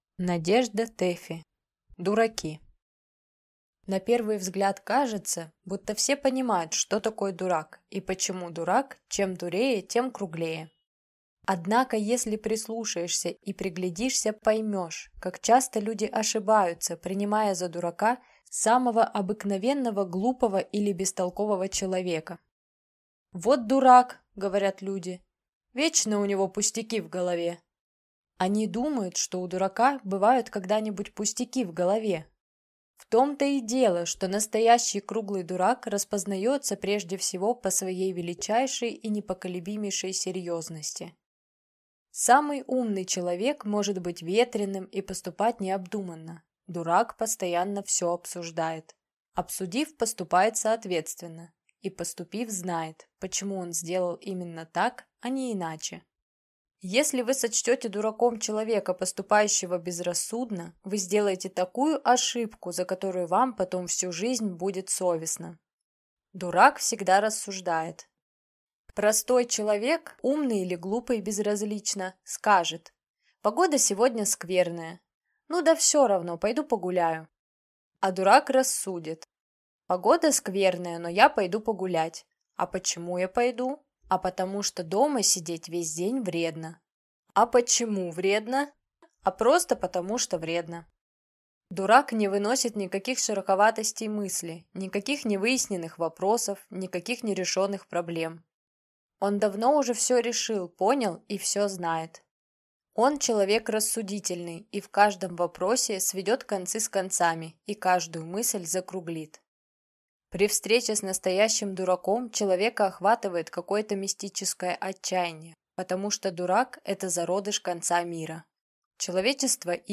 Аудиокнига Дураки | Библиотека аудиокниг